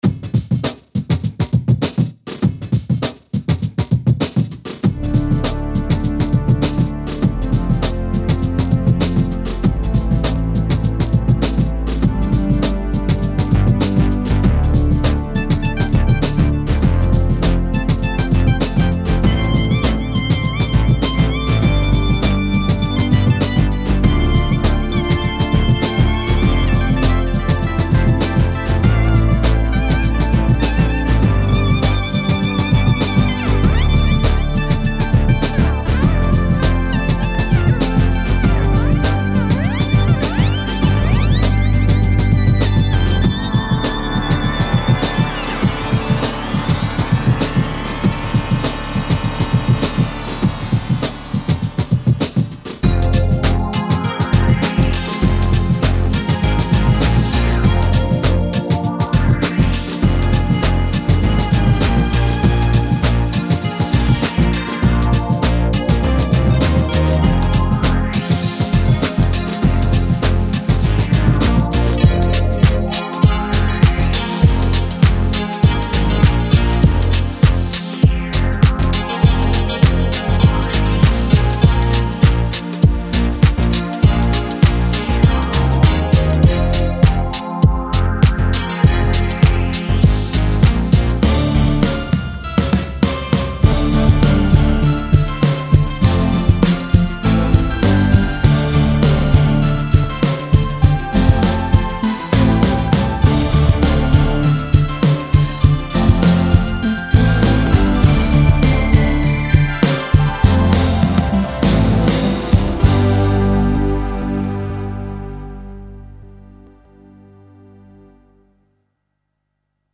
Drums may come from an external source.
Drumloops provided by my Computer.